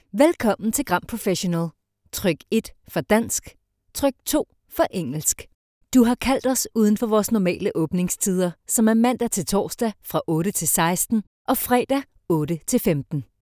Commerciale, Polyvalente, Amicale
Téléphonie